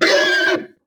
CosmicRageSounds / wav / general / combat / creatures / horse / he / hurt1.wav
hurt1.wav